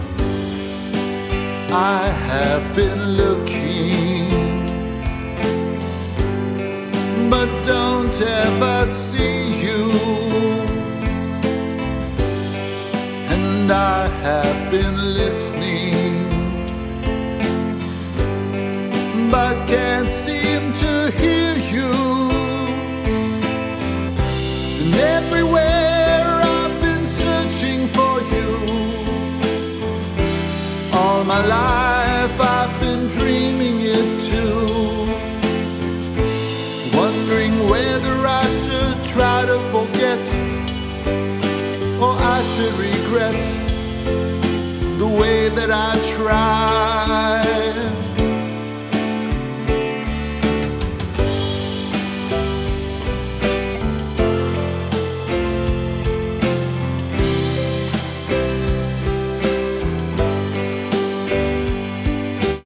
A. Vocal Compositions